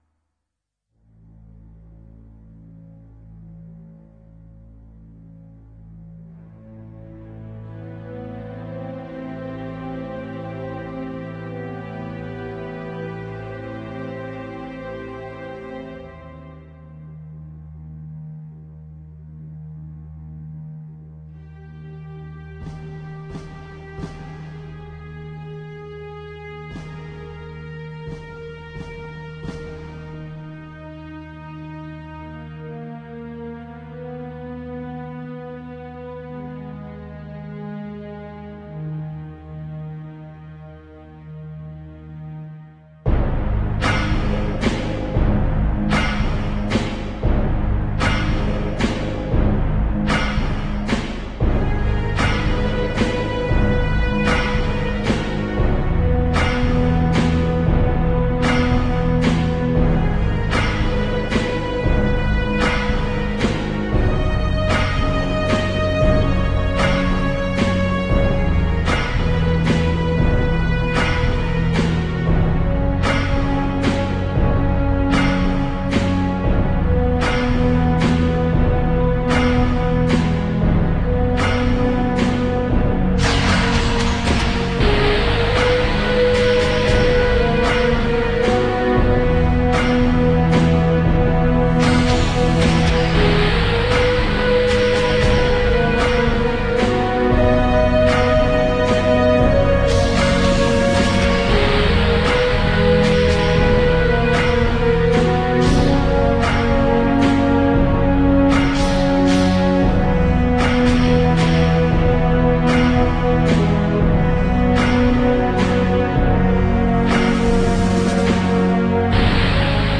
ścieżka dźwiękowa